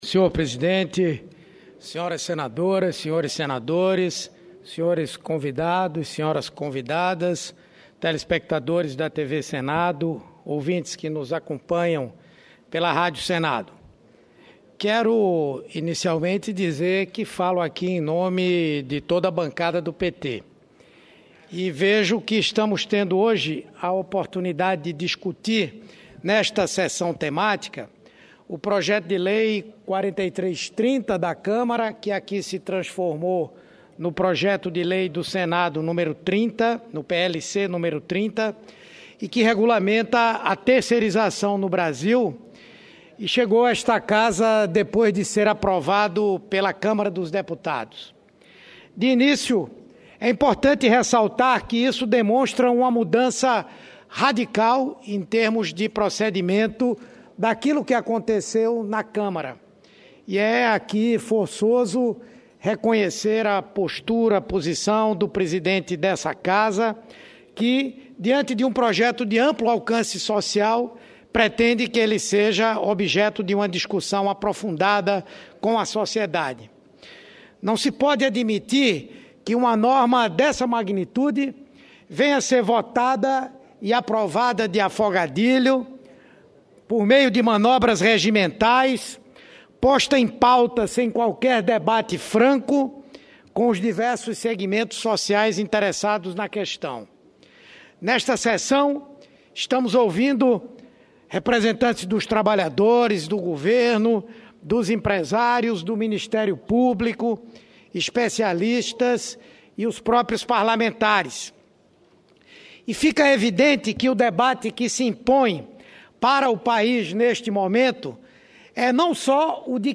Pronunciamento do senador Humberto Costa